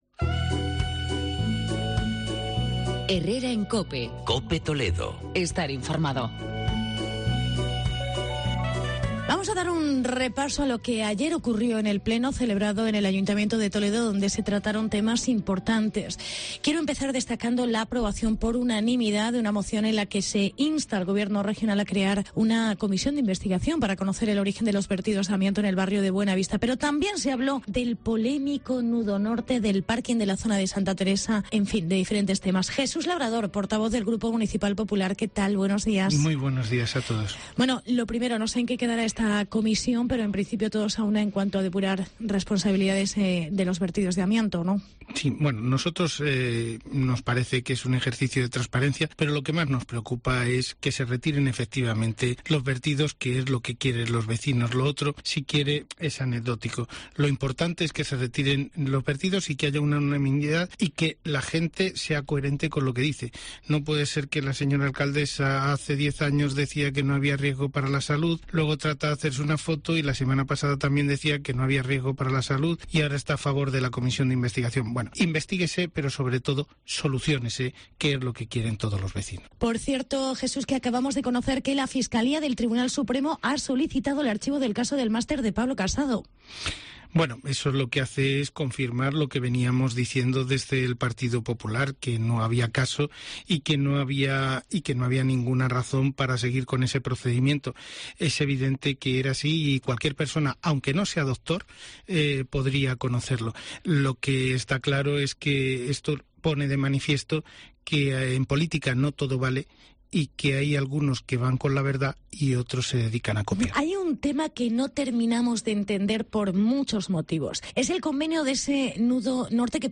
El PP en Toledo sospecha del convenio "Nudo Norte". Entrevista con Jesús Labrador.